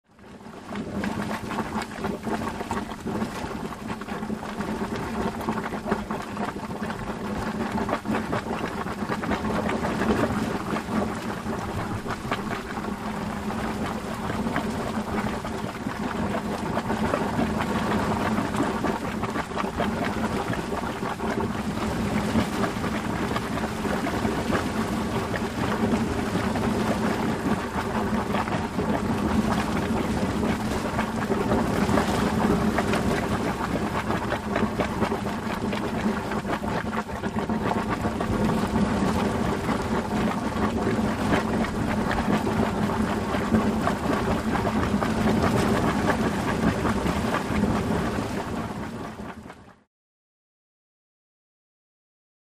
Geyser Water Boiling Rapidly Close Up